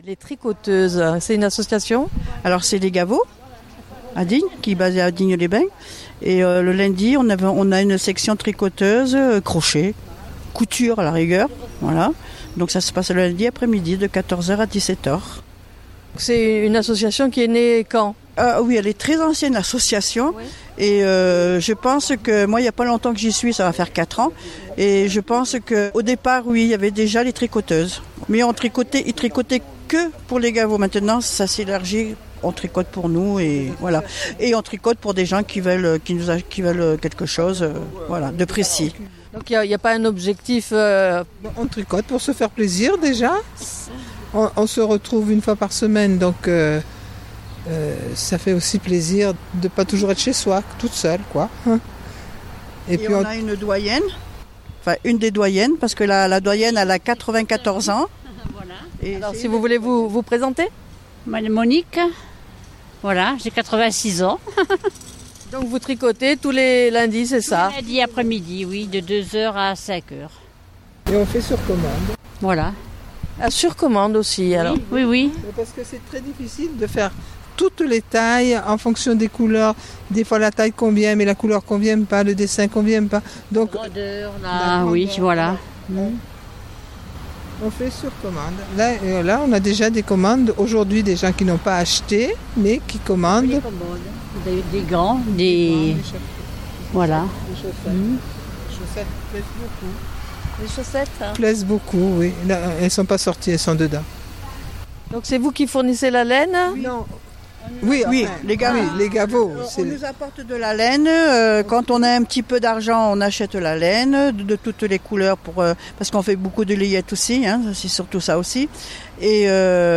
Propos recueillis lors de l'exposition-vente de pulls, gants, bonnets, chaussettes, couvertures etc...devant et dans la salle des Gavots à Digne les Bains. Un lieu pour rompre l'isolement, où sont proposées de nombreuses activités sportives, ainsi que des jeux, scrabble, tarots, également des bals et des sorties, des voyages d'une journée une fois par mois.